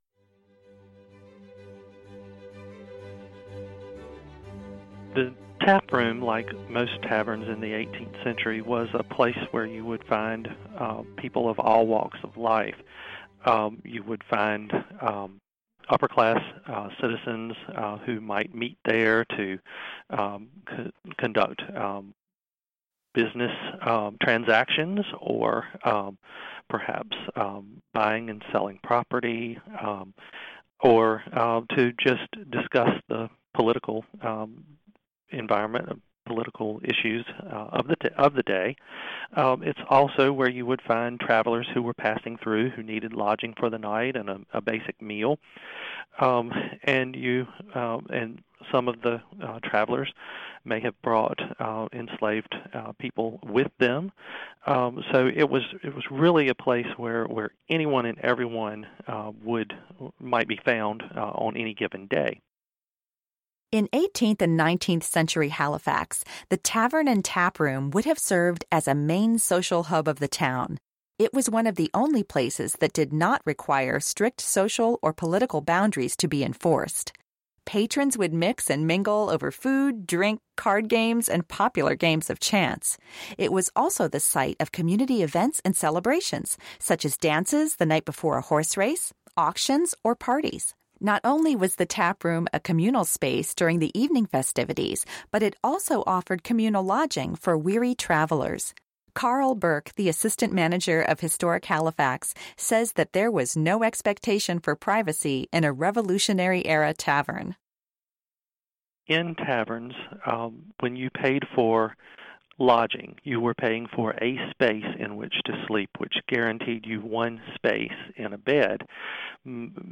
Halifax Tap Room - Audio Tour